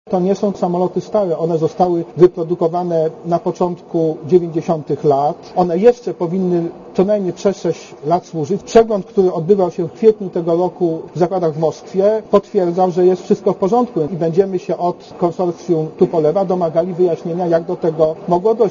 Komentarz audio
samolotzemke.mp3